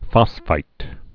(fŏsfīt)